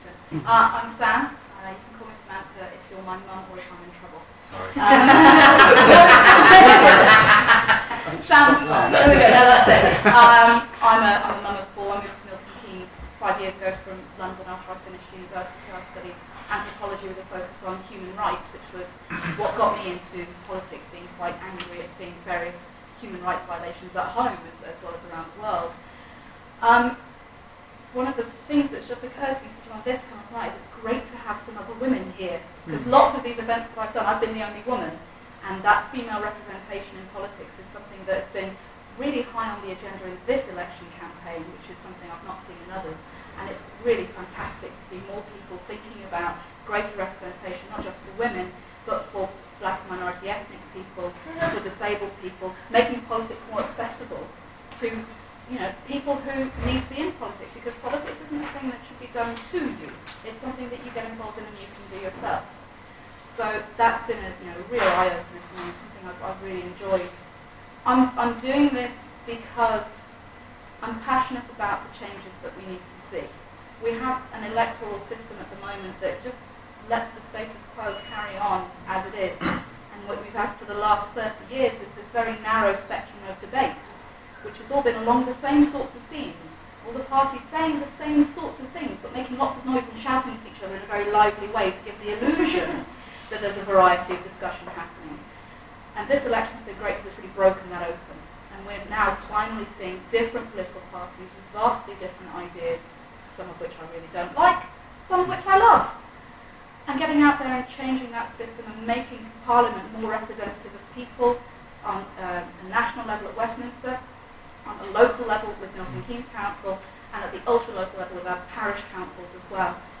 tells an audience at a CitiBlog hustings why they should vote for her on Thursday.